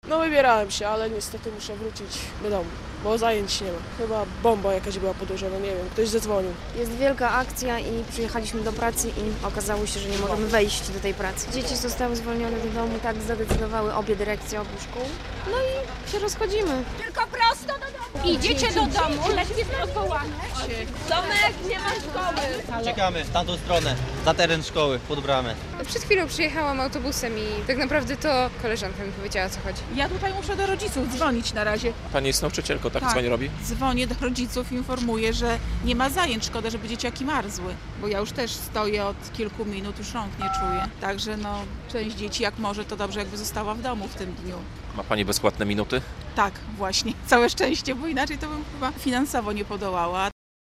Alarm bombowy w szkołach - relacja